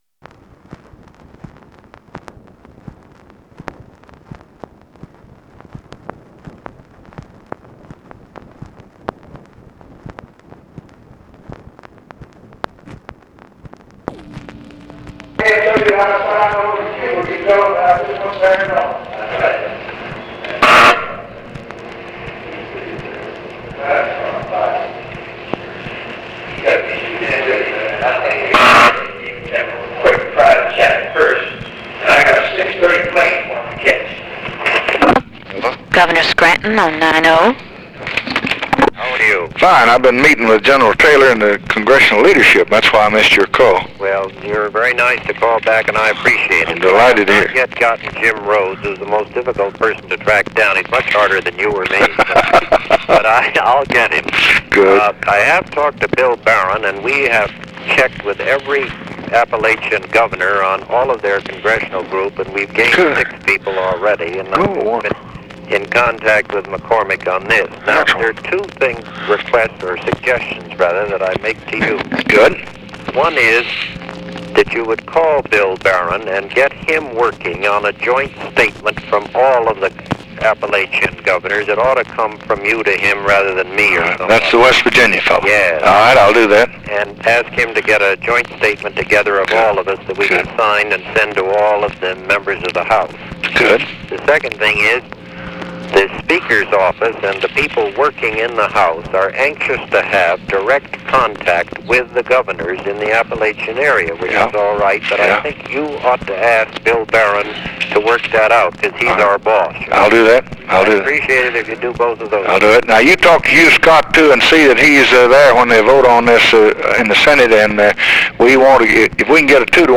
Conversation with WILLIAM SCRANTON and OFFICE CONVERSATION, September 9, 1964
Secret White House Tapes